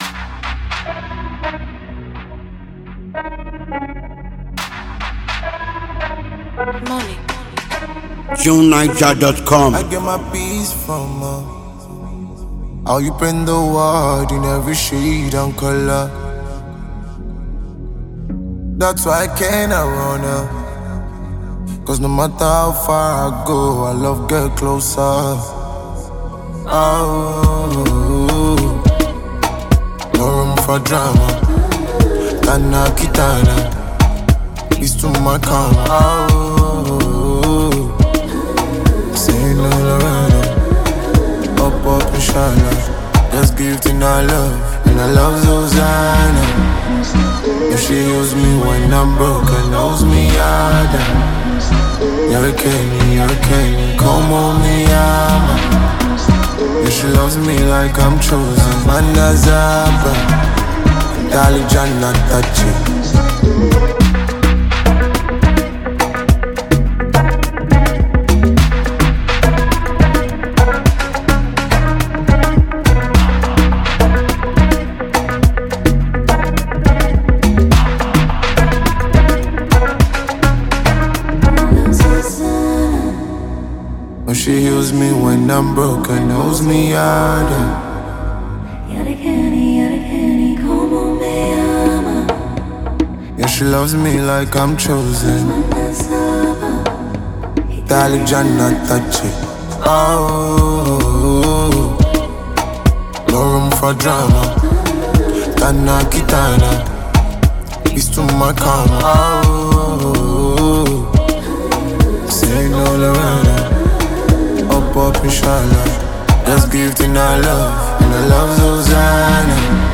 powerful vocal layering and captivating singing style